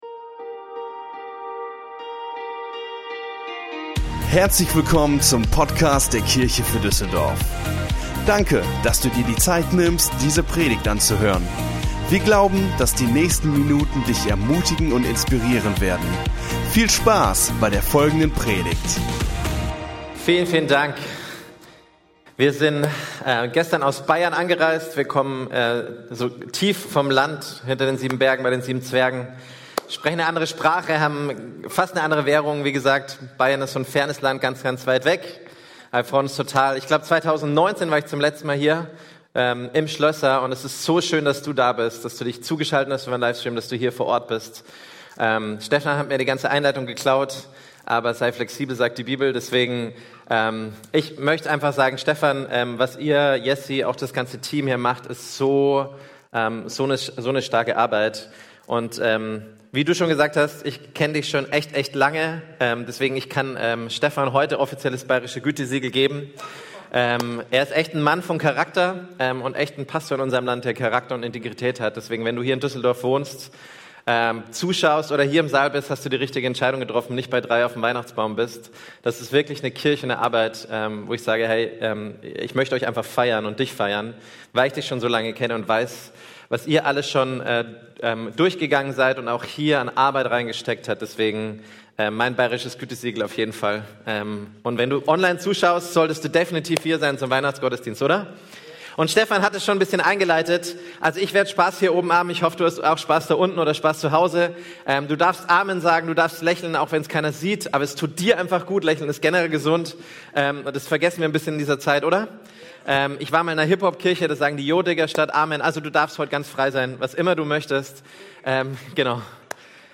Unsere Predigt vom 12.12.21